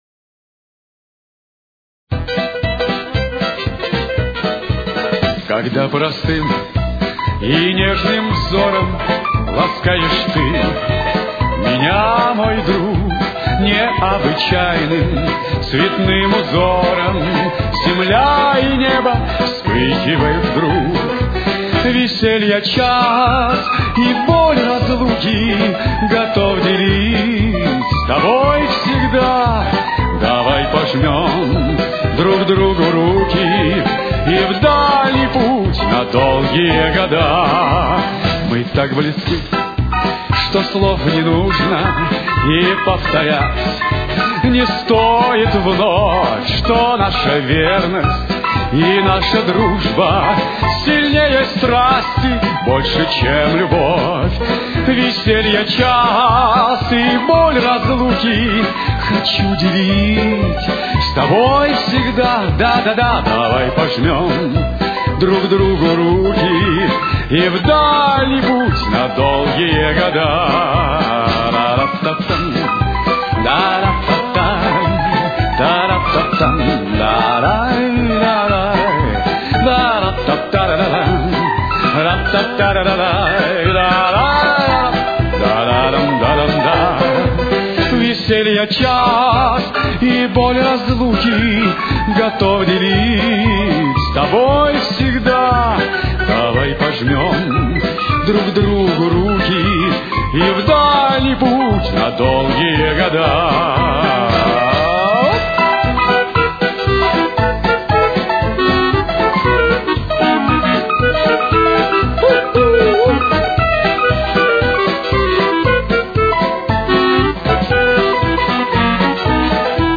с очень низким качеством (16 – 32 кБит/с)
Темп: 119.